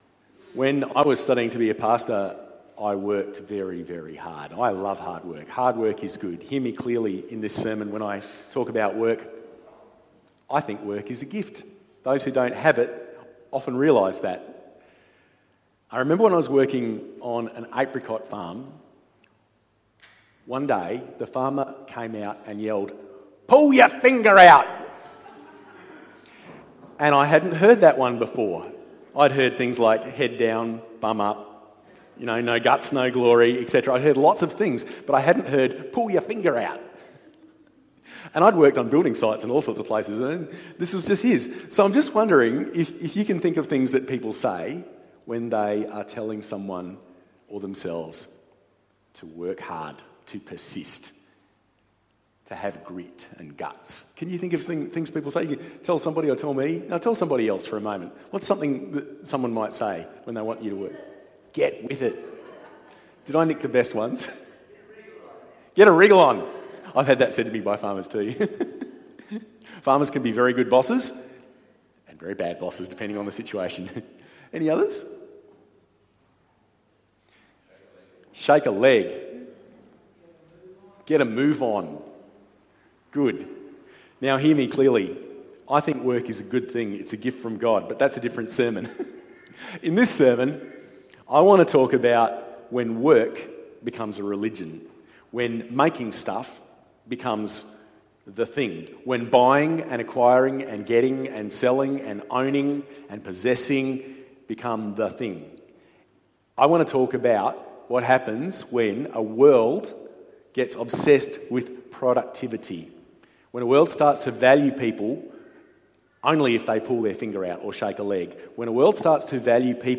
Our series on worry and anxiety has a couple extra bonus sermons!